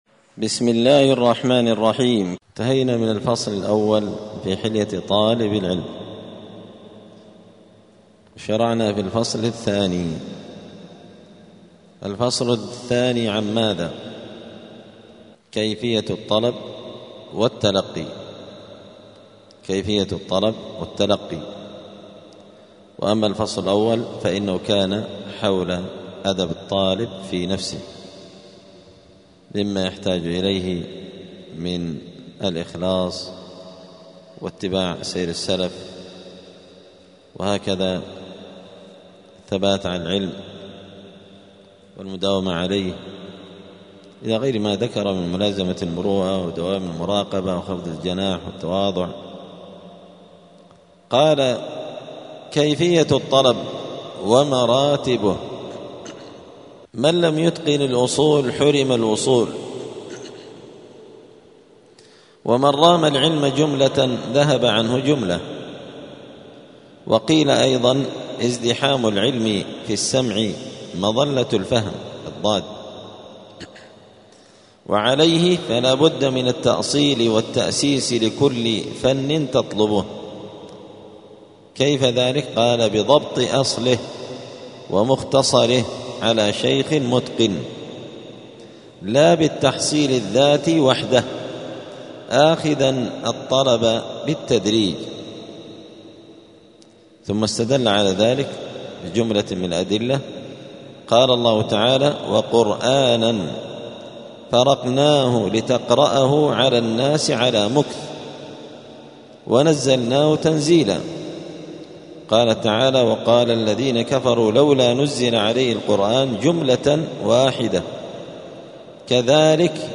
*الدرس السابع عشر (17) {فصل كيفية الطلب ومراتبه}*